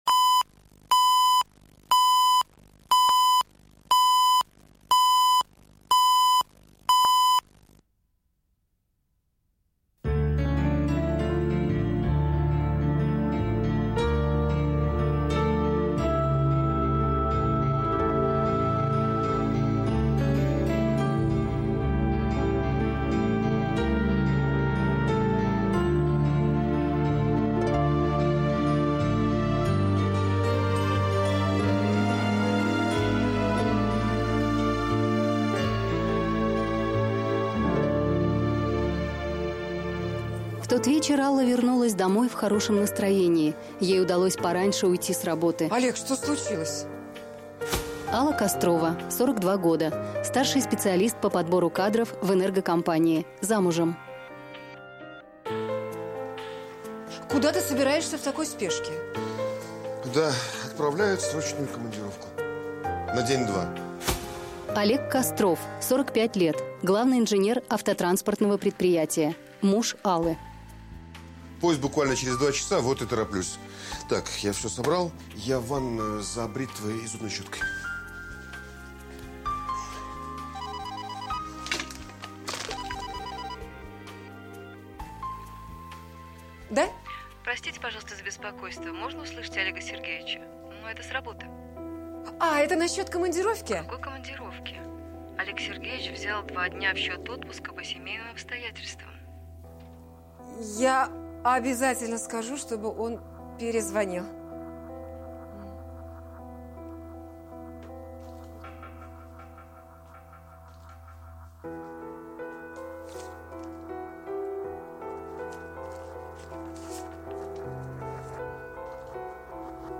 Аудиокнига Вернуть мужа | Библиотека аудиокниг
Aудиокнига Вернуть мужа Автор Александр Левин.